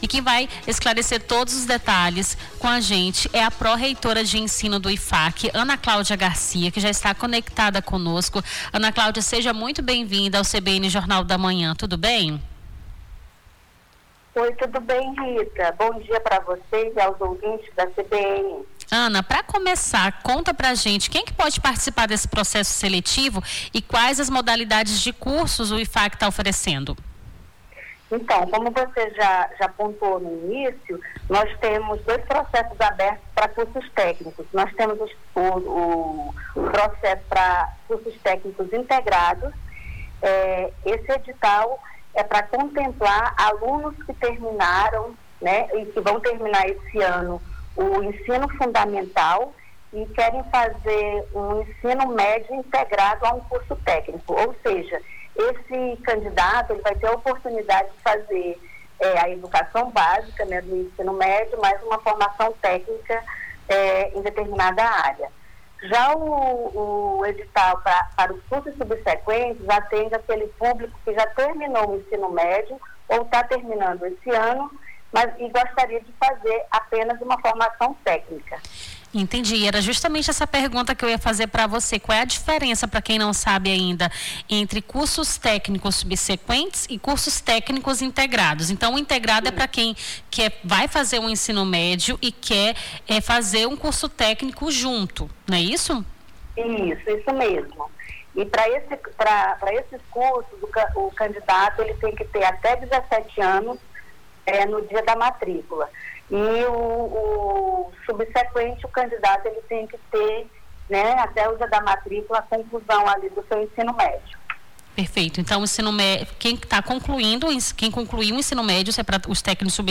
Nome do Artista - CENSURA - ENTREVISTA (CURSOS IFAC) 24-09-25.mp3